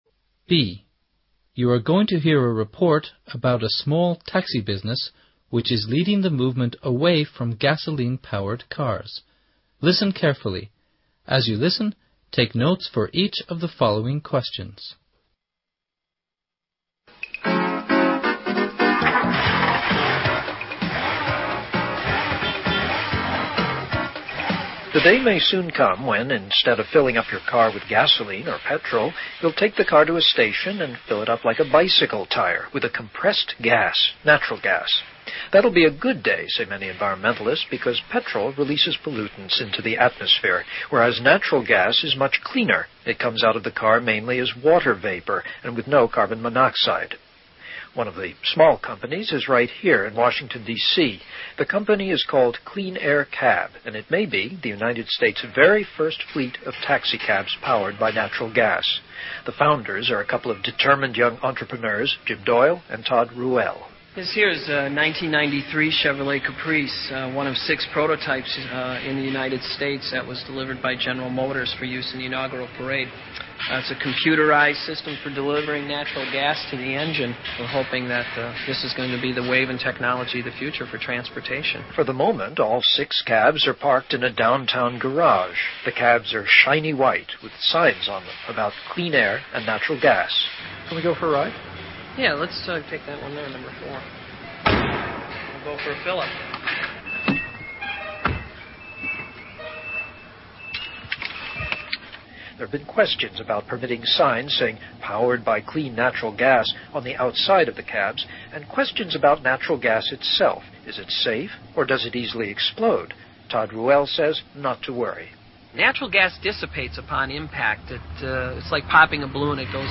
B. You're going to hear a report about a small taxi business which is leading the movement away from gasoline-powered cars, listen carefully, as you listen, take notes for each of the following questions.